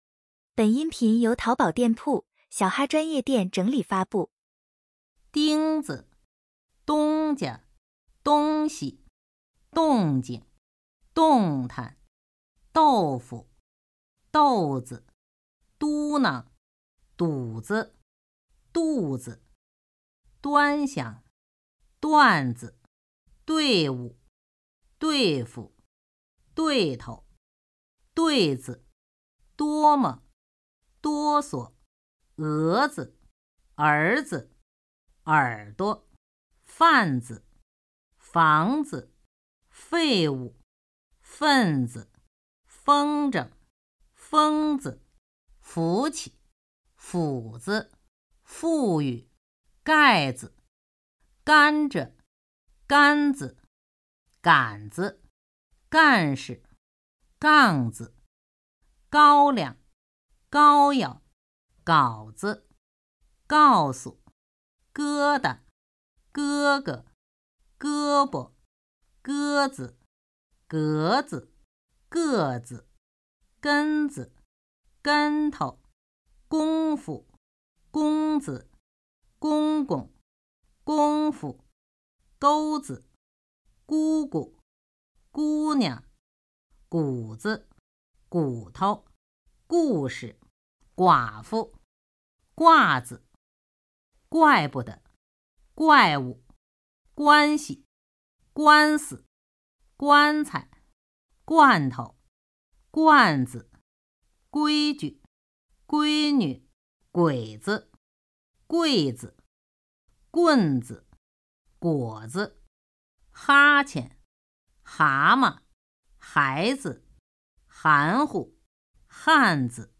轻声101到200.mp3